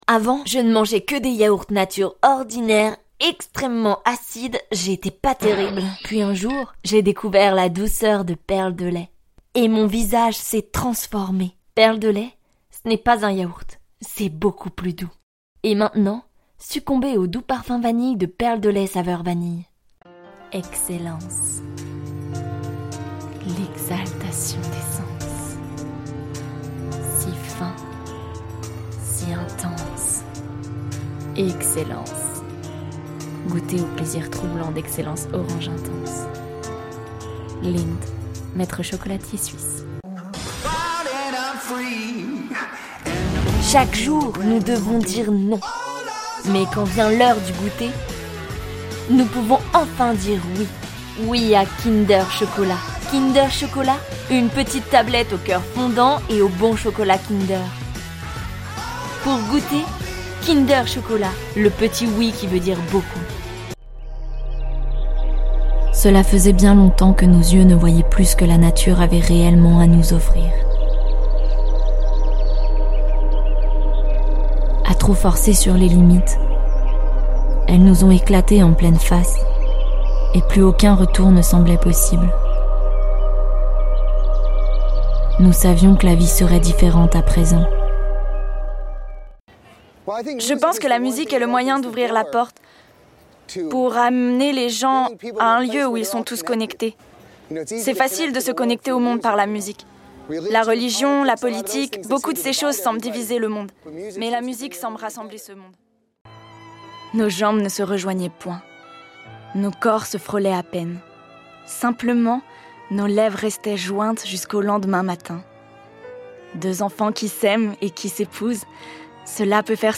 Bandes-son
Voix off
7 - 35 ans - Mezzo-soprano